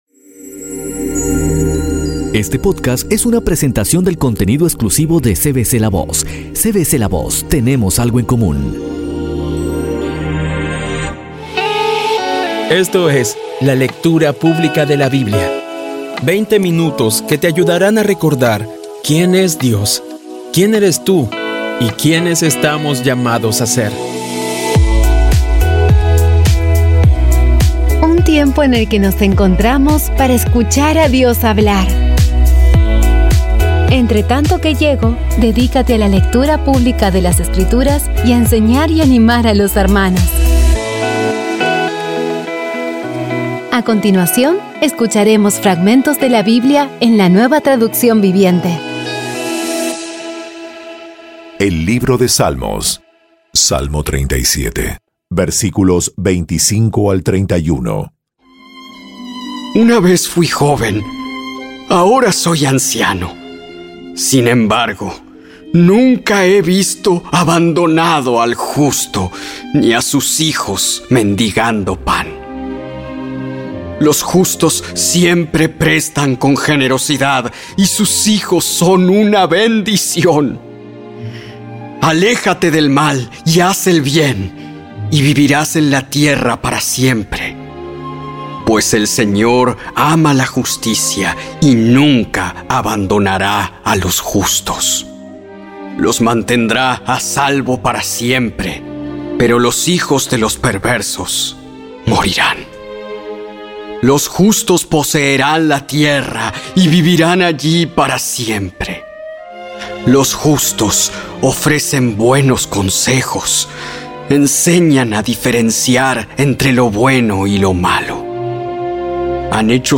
Audio Biblia Dramatizada Episodio 80
Con tan solo veinte minutos diarios, vas cumpliendo con tu propósito de estudiar la Biblia completa en un año. Poco a poco y con las maravillosas voces actuadas de los protagonistas vas degustando las palabras de esa guía que Dios nos dio.